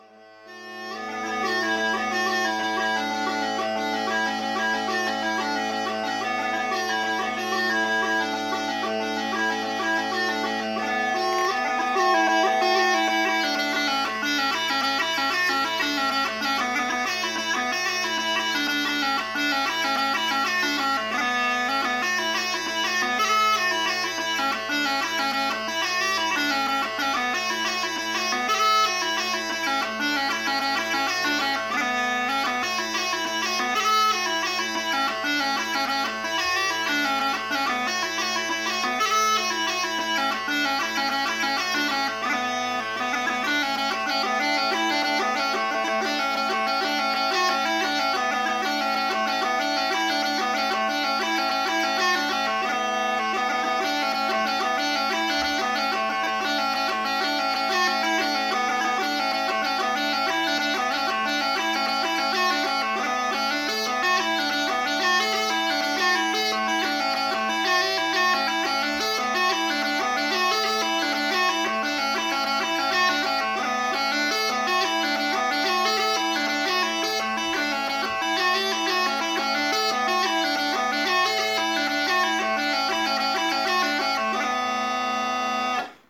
Hornpipe